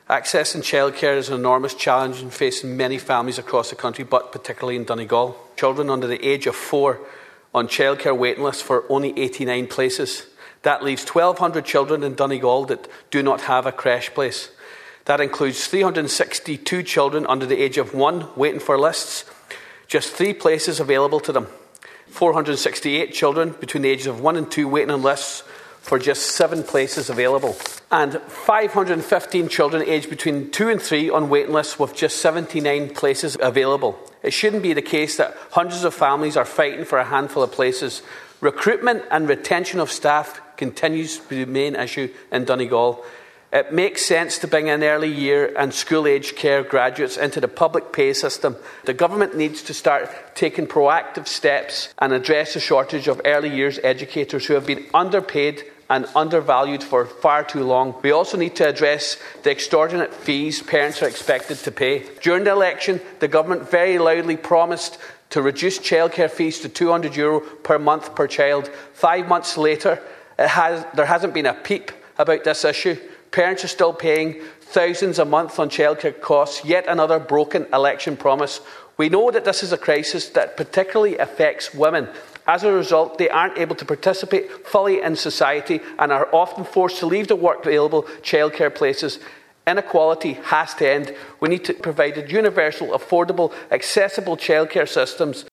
Speaking in the Dáil, he raised a recent survey by Early Childhood Ireland that revealed there are 1,345 children under the age of 4 on childcare waiting lists in the county, while only 89 places are available.